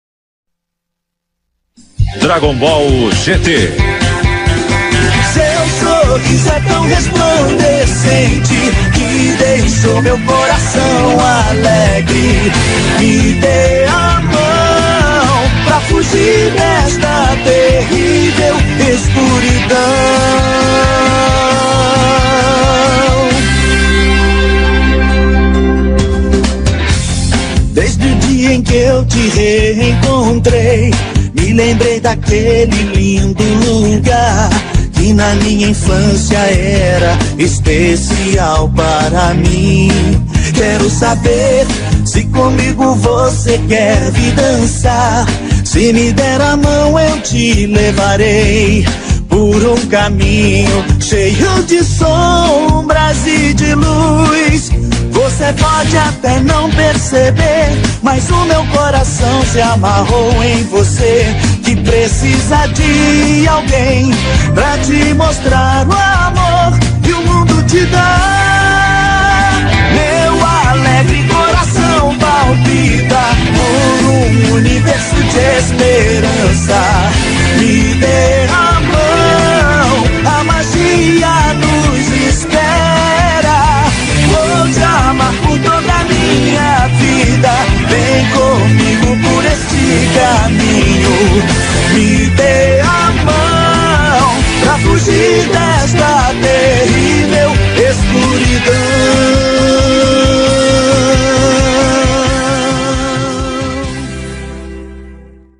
2024-11-01 00:28:35 Gênero: Rap Views